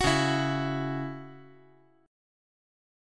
Listen to C#mbb5 strummed